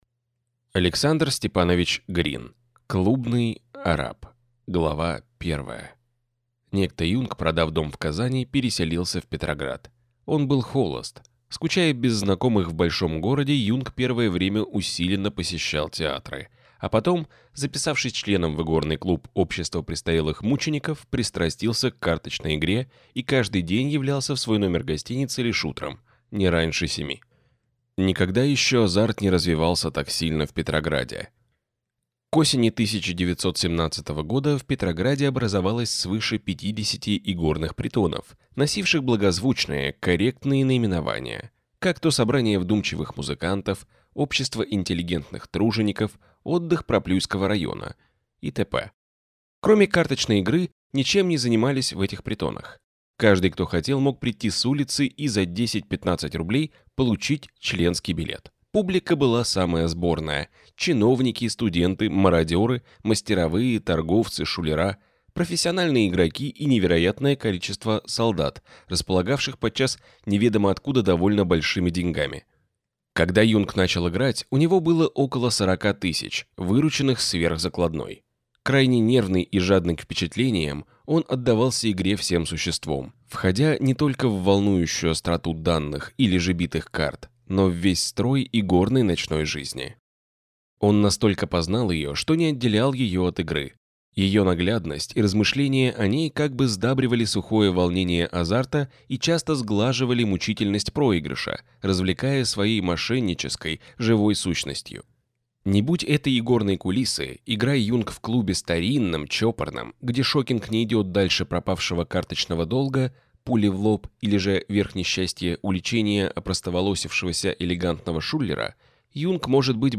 Аудиокнига Клубный арап | Библиотека аудиокниг
Прослушать и бесплатно скачать фрагмент аудиокниги